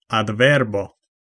Ääntäminen
Ääntäminen France: IPA: /ad.vɛʁb/ Haettu sana löytyi näillä lähdekielillä: ranska Käännös Konteksti Ääninäyte Substantiivit 1. adverbo kielioppi Suku: m .